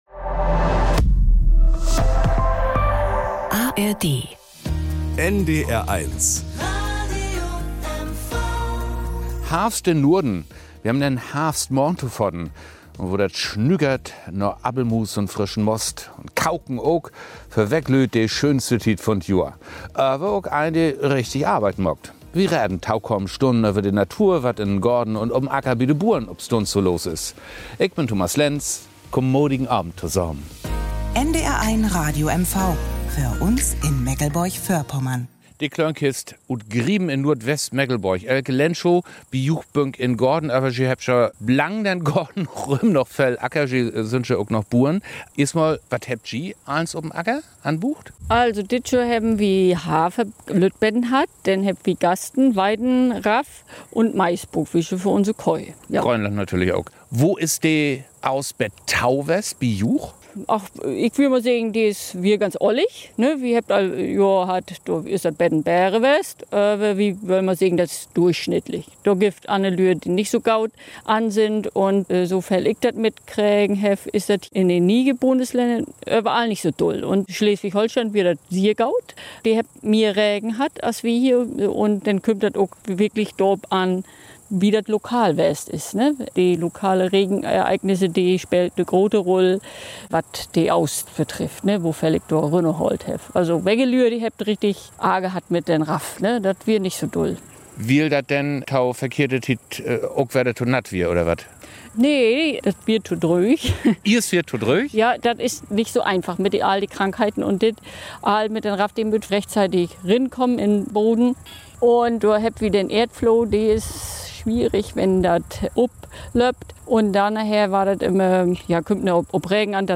De Klönkist mit einer Plauderei über Ernte und den Bauerngarten im Herbst ~ Plattdeutsches aus MV Podcast